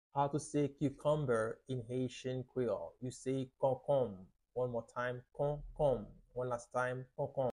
How to say “Cucumber” in Haitian Creole – “Konkonm” pronunciation by a native Haitian Teacher
“Konkonm” Pronunciation in Haitian Creole by a native Haitian can be heard in the audio here or in the video below:
How-to-say-Cucumber-in-Haitian-Creole-–-Konkonm-pronunciation-by-a-native-Haitian-Teacher.mp3